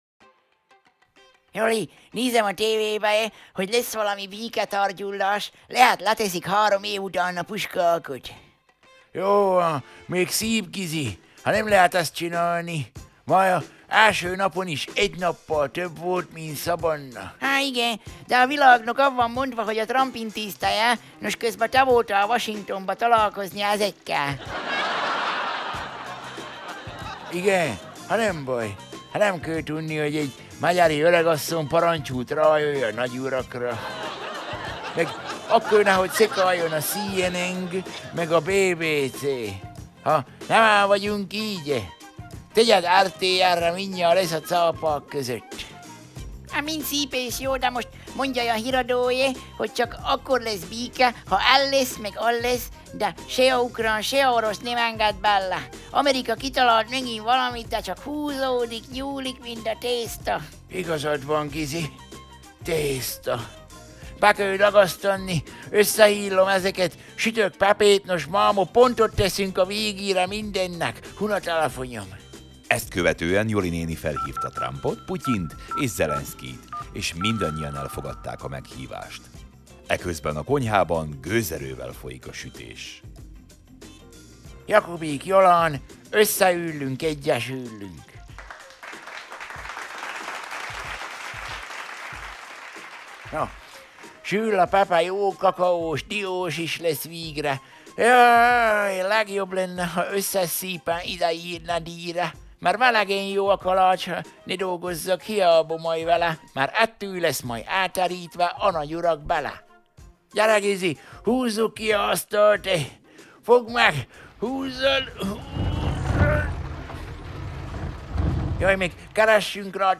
Szaval a jó öreg csallóközi Szaval a jó öreg csallóközi: Összeűllünk, ëggyesűllünk!